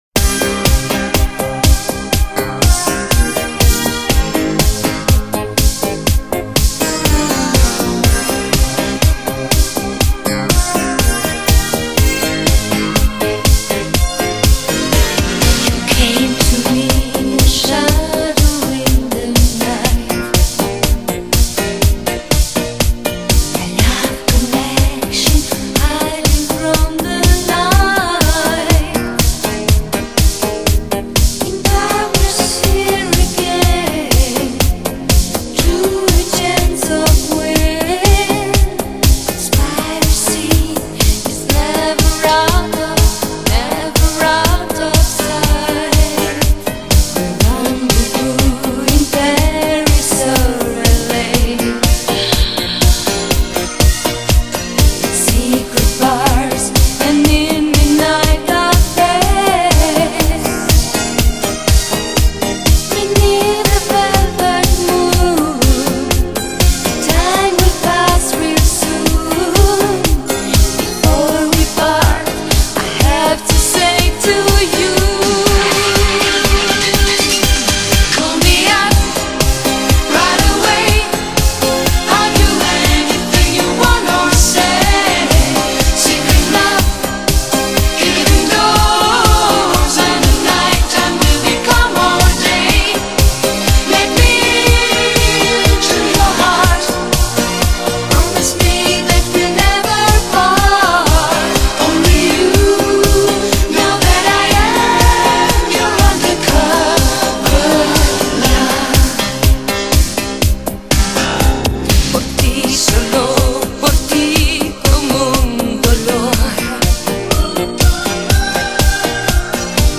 Electronic / Synth-pop / 1986